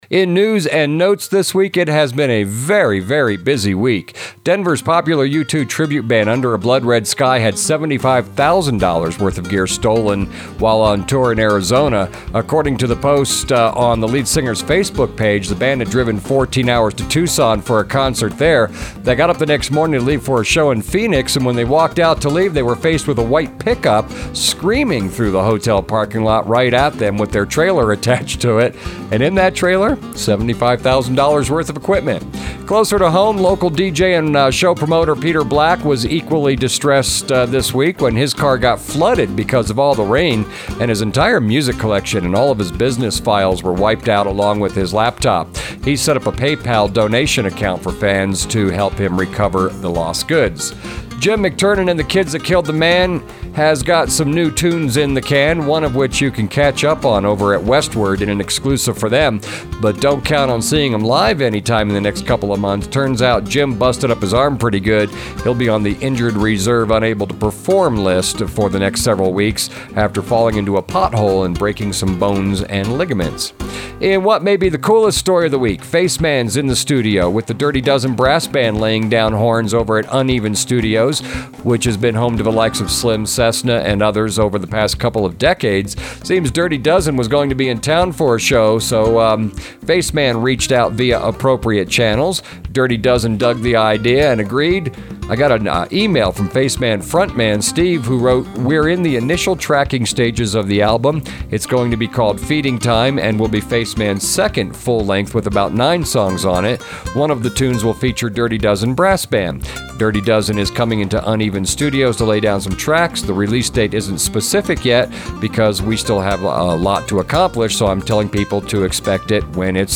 (music bed = Ecstatico by Dave Beegle, from his album Beyond the Desert, courtesy of Avant Acoustic Records)